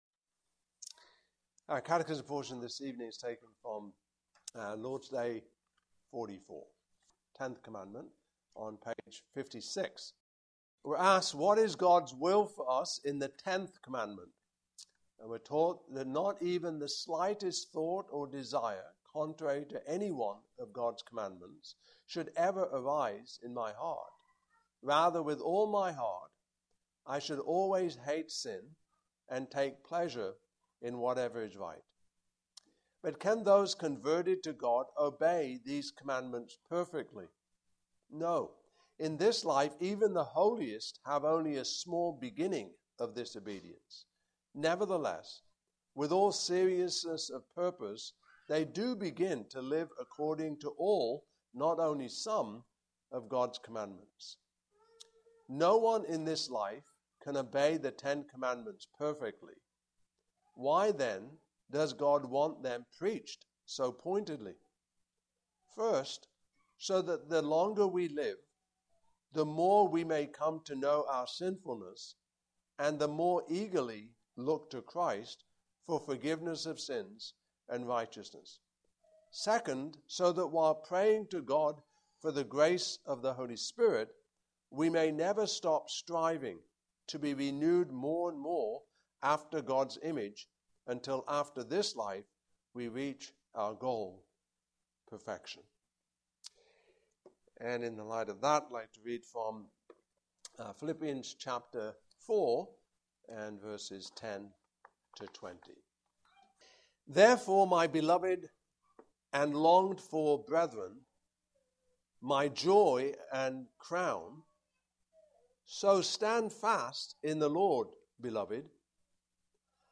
Passage: Philippians 4:10-20 Service Type: Evening Service